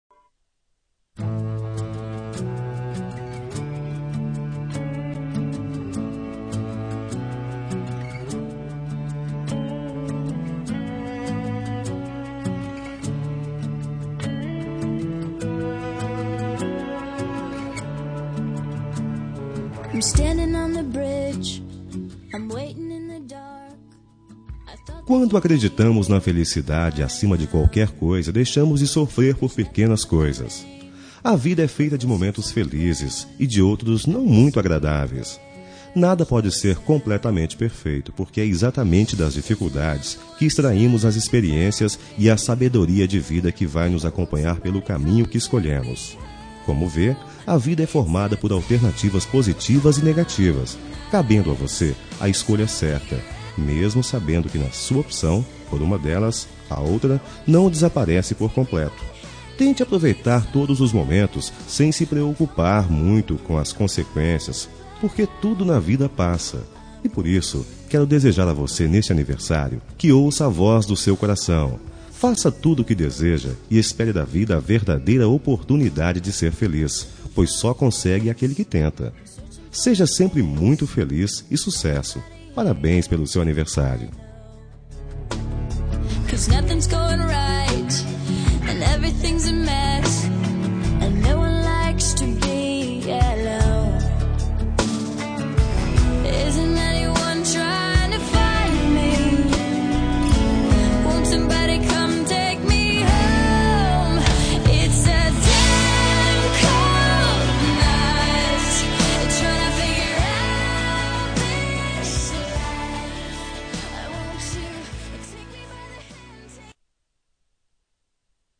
Telemensagem de Aniversário de Pessoa Especial – Voz Masculino – Cód: 1913